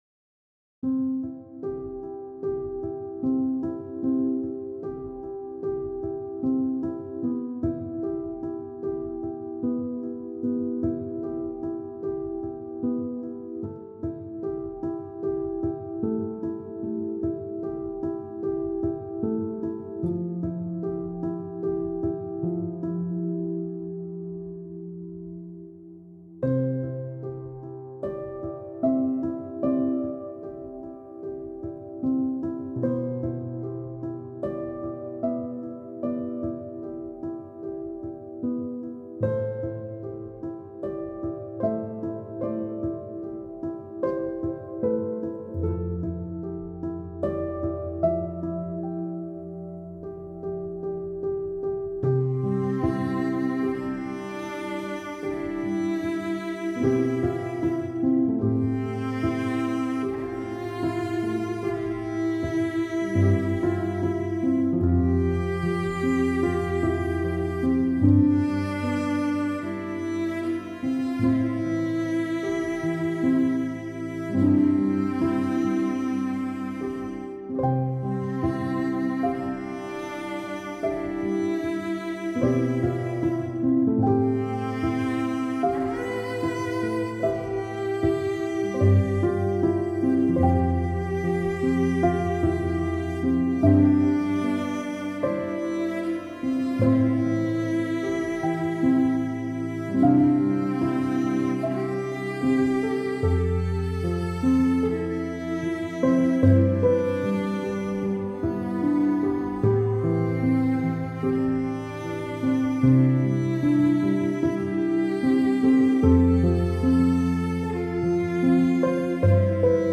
موسیقی بی کلام و ملایمی که نباید از دست داد!
آخرین خبر/ موزیک بی کلام «You were my last breathe» تقدیم به شما.